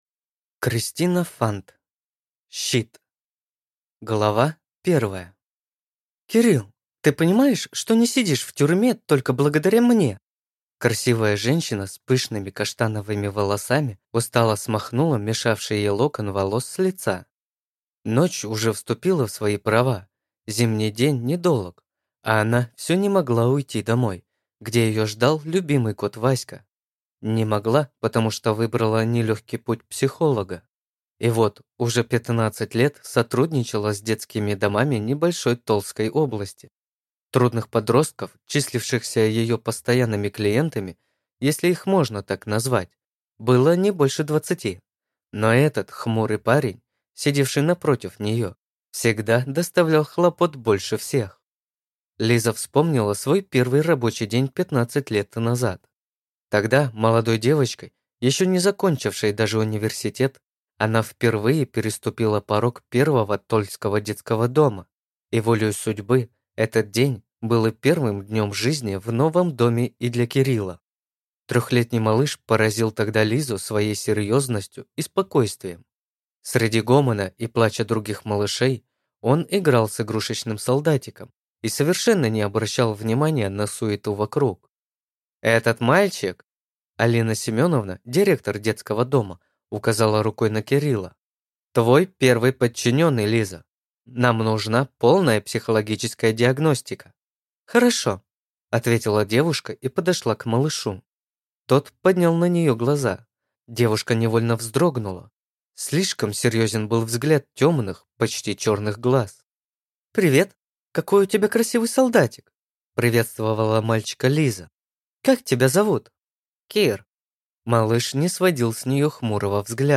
Аудиокнига Щит | Библиотека аудиокниг
Читает аудиокнигу